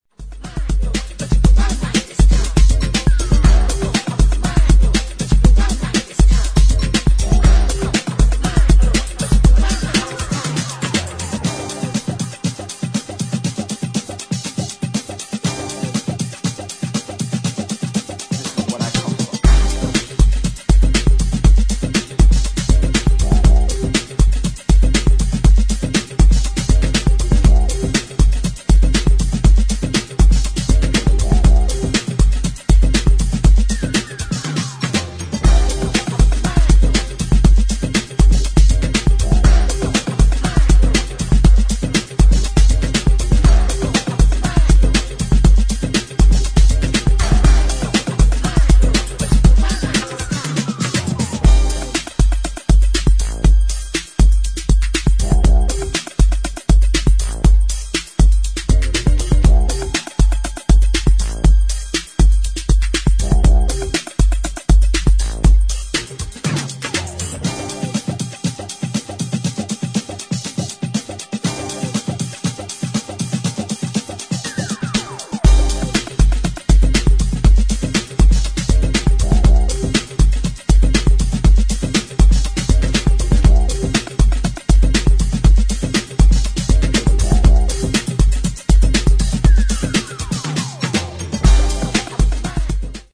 [ HOUSE / BROKEN BEAT ]